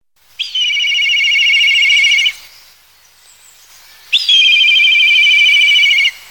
Milan noir, milvus migrans